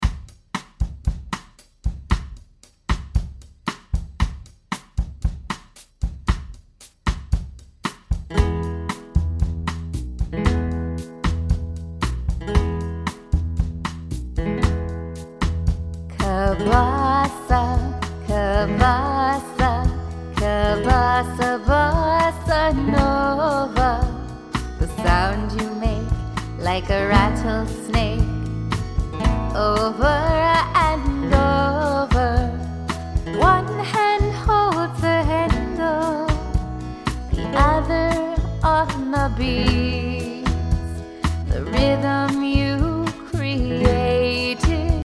A song for music class teaching Bossa nova with the cabasa!